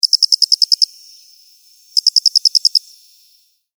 自然・動物 （58件）
ミツカドコオロギ短め.mp3